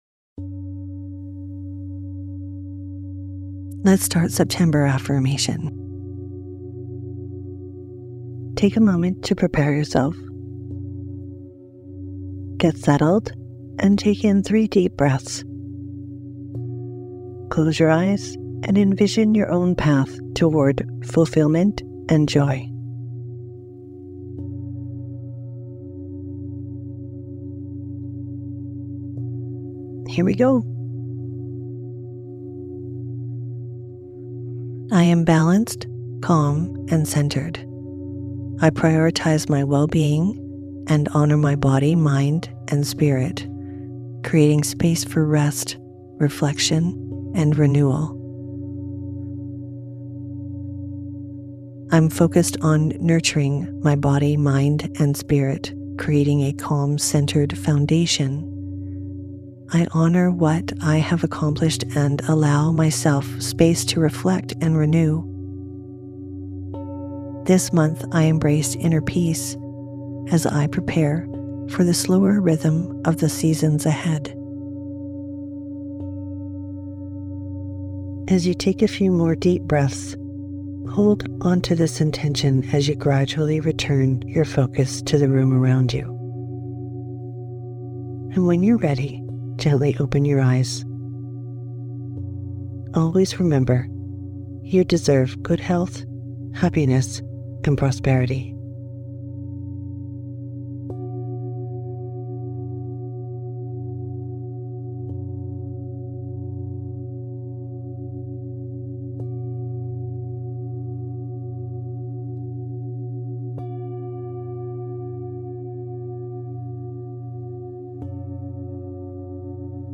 September Affirmation Mini Meditation | Affirmations for a Purposeful Year